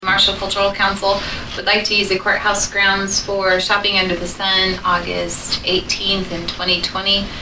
Northern District Commissioner Stephanie Gooden explained.